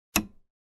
Звуки телевизора
На этой странице собраны разнообразные звуки телевизора: от характерного писка при включении до статичных помех и переключения каналов.